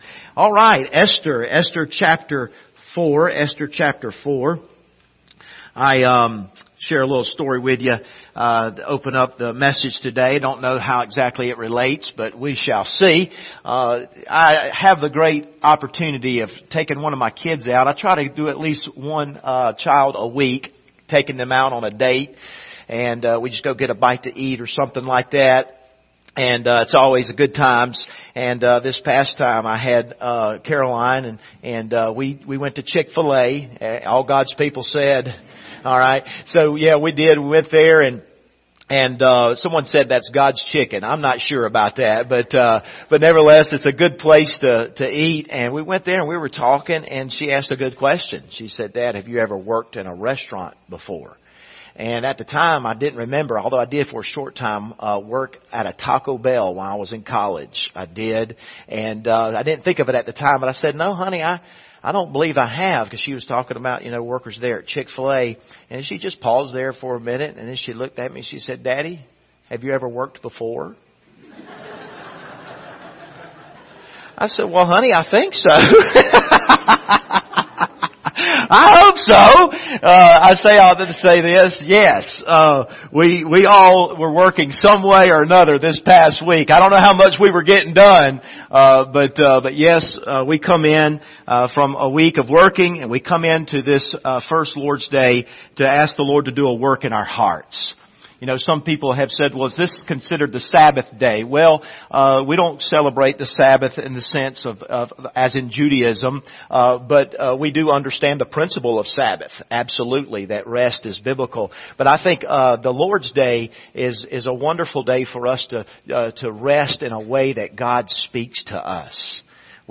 Esther Passage: Esther 4 Service Type: Sunday Morning View the video on Facebook « Stewarding Time Lesson 2 Being Scared to Life